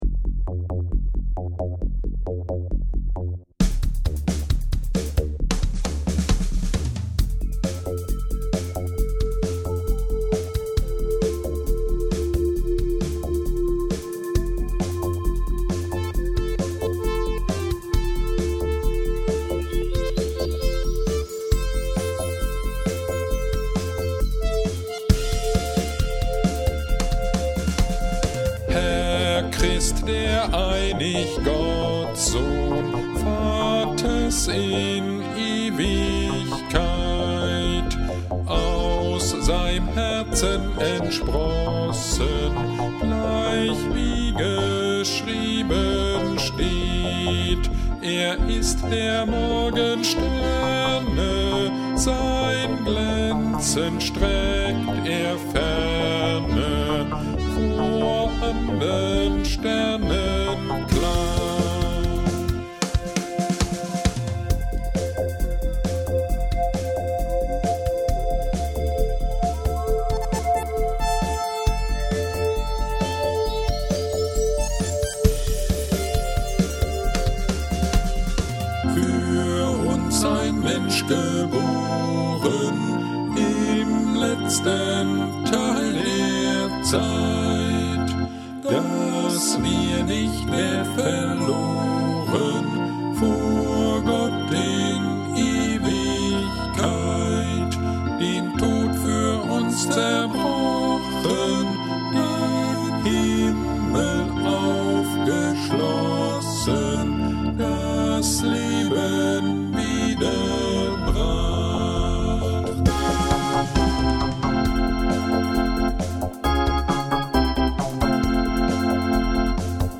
Predigt zur Jahreslosung 2022 aus Johannes 6.37b